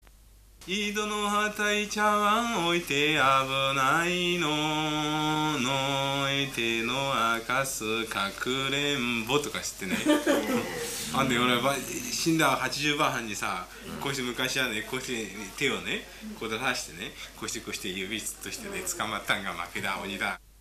いずものあたいちゃわん 遊戯歌